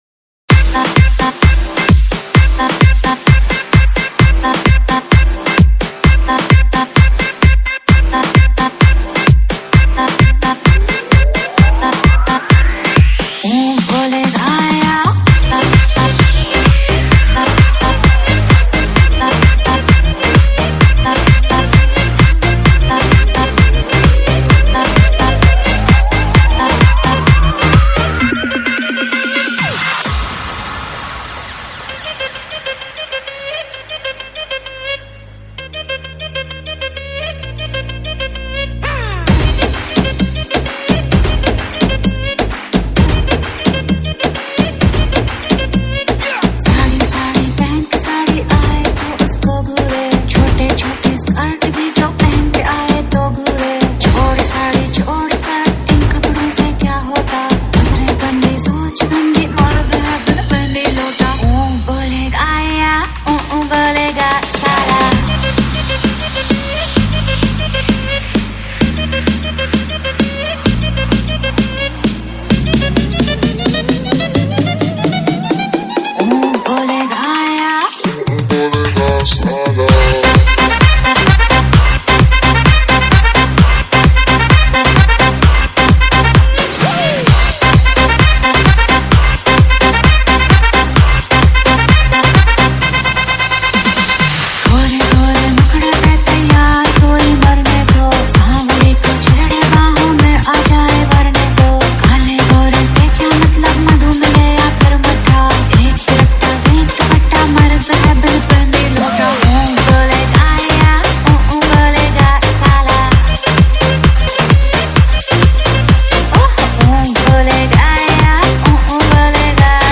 Hindi Dj Remix songs